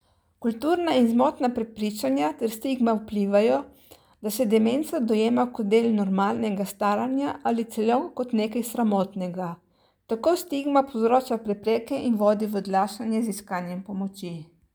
Tonske izjave: